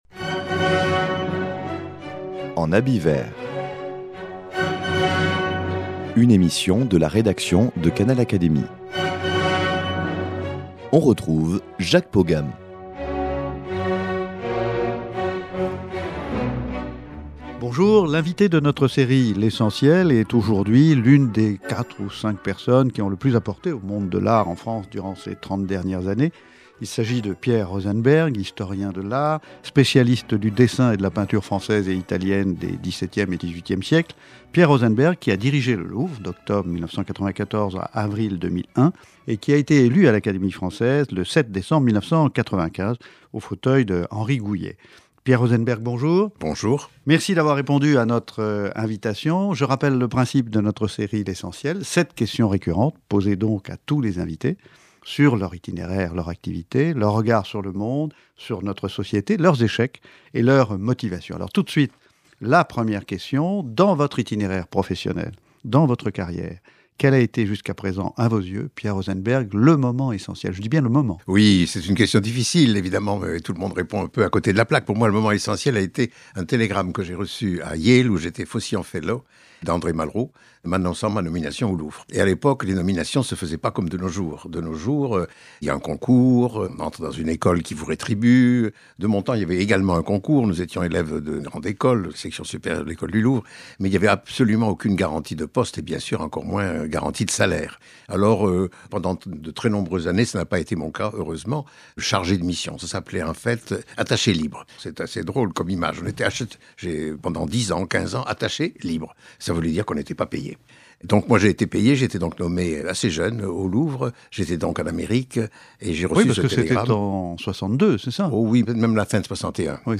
L’invité de notre série l’Essentiel est aujourd’hui l’une des quatre ou cinq personnes qui ont le plus apporté au monde de l’art en France durant ces trente dernières années : Pierre Rosenberg, historien de l’art, spécialiste du dessin et de la peinture française et italienne des XVII e et XVIII e siècles.